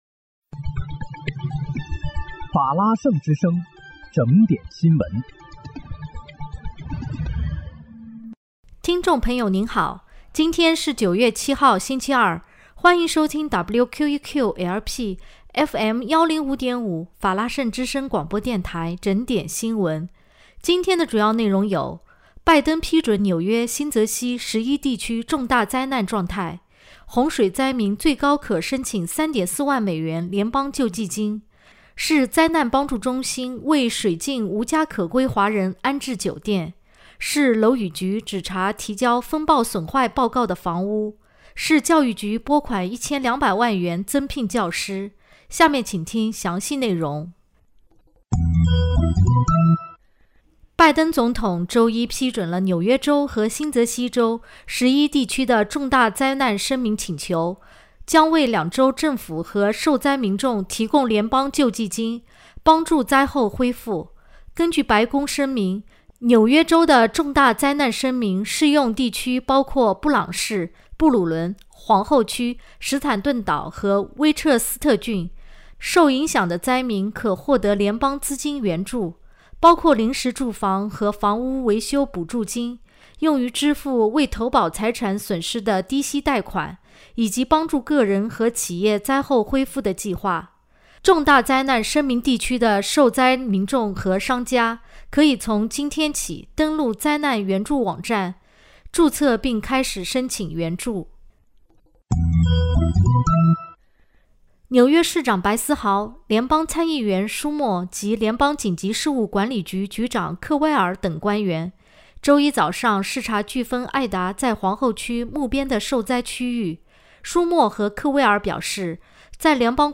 9月7日（星期二）纽约整点新闻